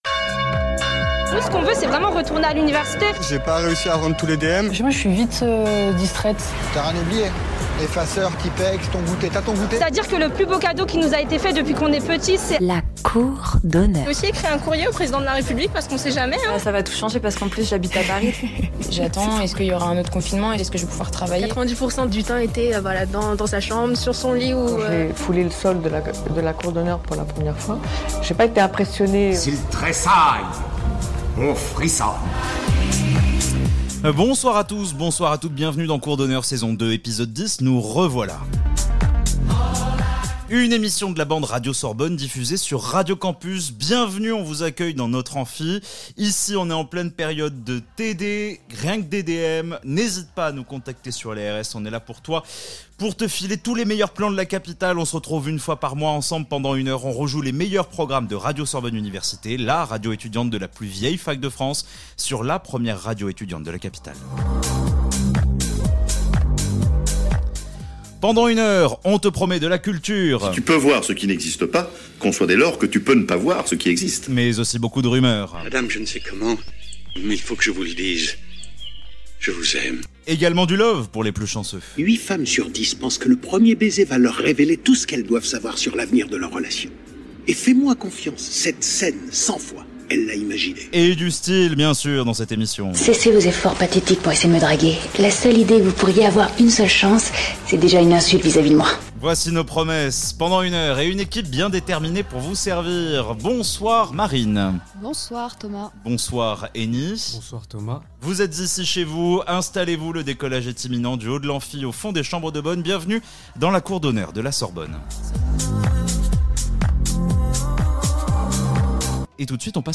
Les étudiants de Radio Sorbonne Université piratent Radio Campus Paris